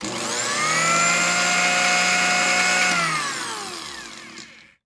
blender-sound